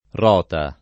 r0ta] s. f. — in generale, variante pop. o lett. di ruota — più spesso rota in alcuni sign., tra cui quello di «tribunale»: la Sacra Romana Rota, le decisioni della Rota; in passato, anche la rota civile, criminale, la rota fiorentina, genovese, ecc. — sim. il top. e cogn. Rota